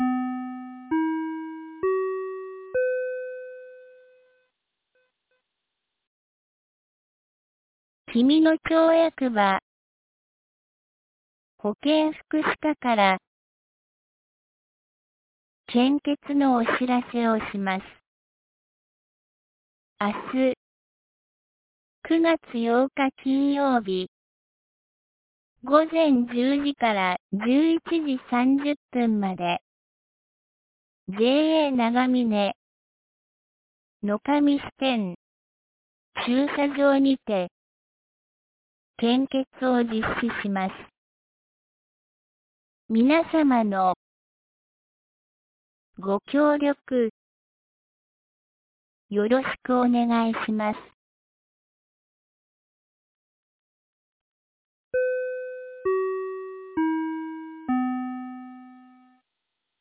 2023年09月07日 17時06分に、紀美野町より全地区へ放送がありました。